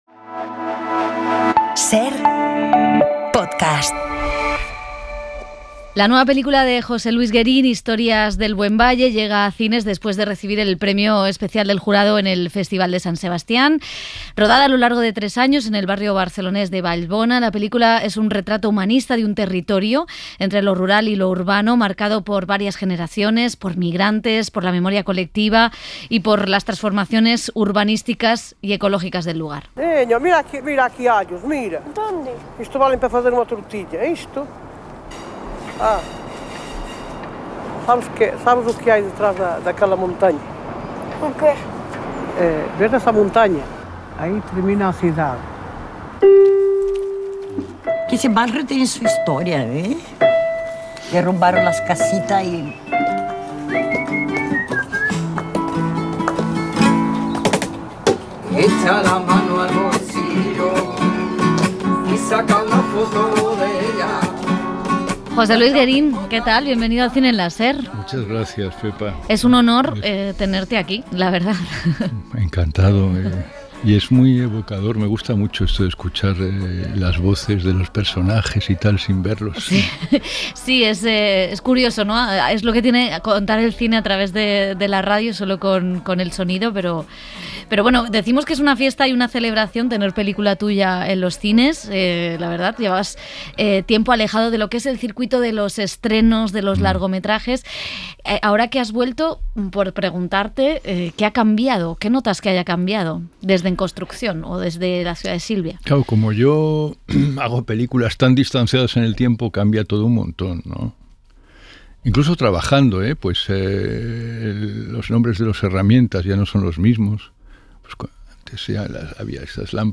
Entrevista | José Luis Guerin y cómo capturar la vida en ‘Historias del buen valle’